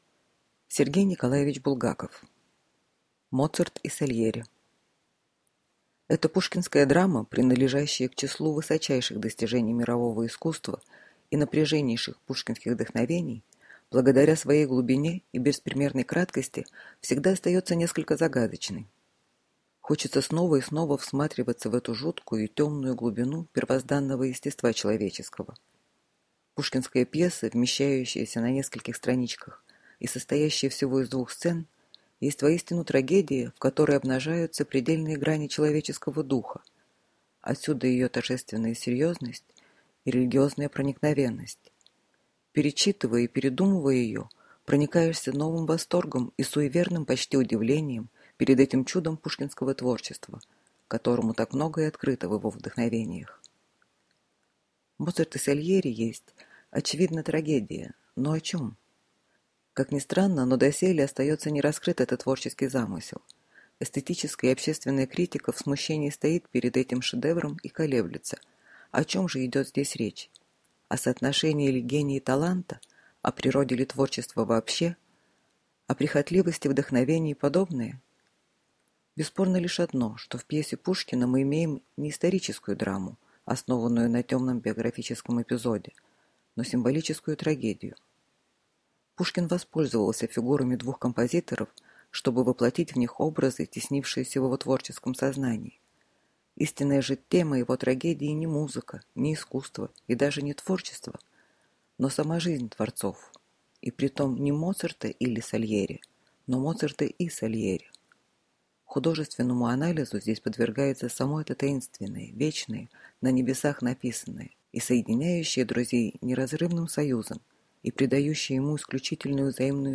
Аудиокнига Моцарт и Сальери | Библиотека аудиокниг